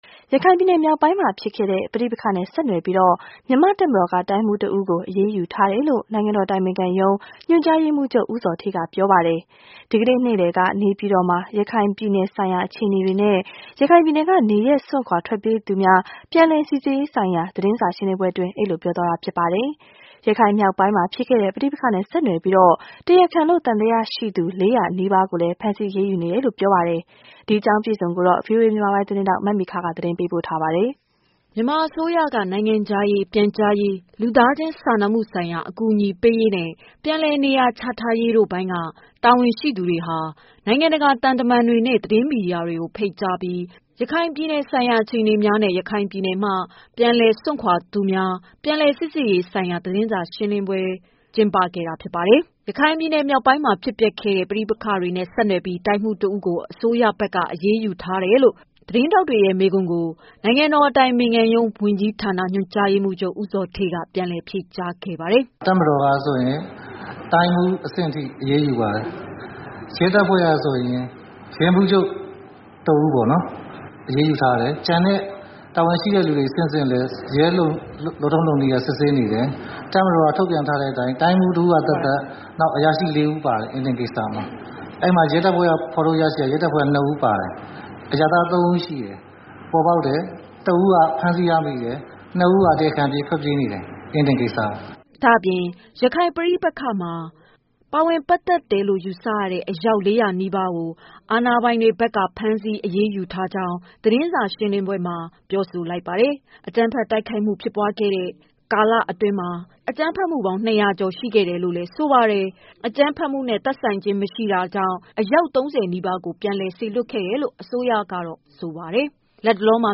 ဒီကနေ့ နေ့လည်က နေပြည်တော်မှာ ရခိုင်ပြည်နယ်ဆိုင်ရာ အခြေအနေများနဲ့ ရခိုင်ပြည်နယ်က နေရပ်စွန့်ခွာ ထွက်ပြေးသူများ ပြန်လည် စိစစ်ရေးဆိုင်ရာ သတင်းစာ ရှင်းလင်းပွဲ အတွင်း အဲဒီလို ပြောကြားလိုက်တာပါ။ ရခိုင်မြောက်ပိုင်းမှာ ဖြစ်ခဲ့တဲ့ ပဋိပက္ခနဲ့ ဆက်နွယ်ပြီး တရားခံလို့ သံသရရှိသူ ၄၀၀နီးပါးကိုလည်း ဖမ်းဆီး အရေး ယူနေတယ်လို ပြောပါတယ်။
by ဗွီအိုအေသတင်းဌာန